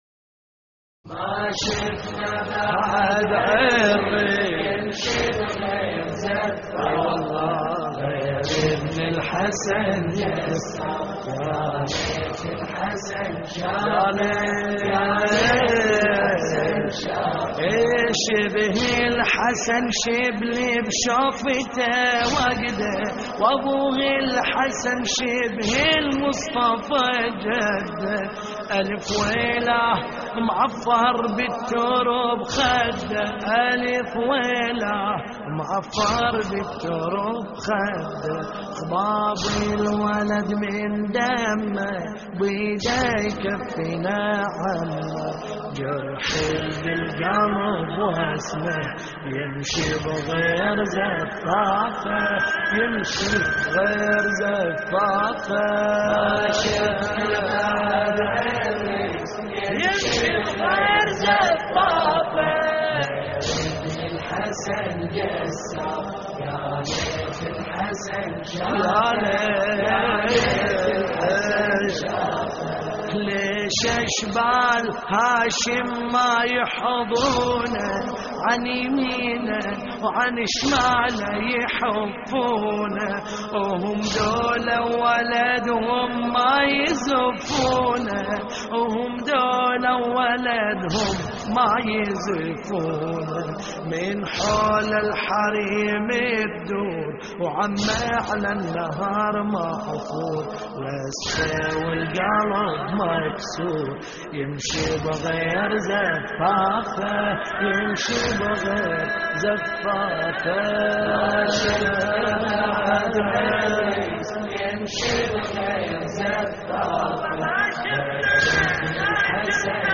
استديو